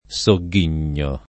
vai all'elenco alfabetico delle voci ingrandisci il carattere 100% rimpicciolisci il carattere stampa invia tramite posta elettronica codividi su Facebook sogghignare v.; sogghigno [ S o gg& n’n’o ] — coniug. come ghignare